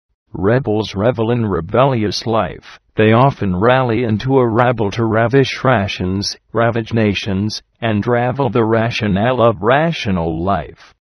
唸音